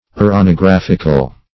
Meaning of uranographical. uranographical synonyms, pronunciation, spelling and more from Free Dictionary.
Search Result for " uranographical" : The Collaborative International Dictionary of English v.0.48: Uranographic \U`ra*no*graph"ic\, Uranographical \U`ra*no*graph"ic*al\, a. Of or pertaining to uranography; as, an uranographic treatise.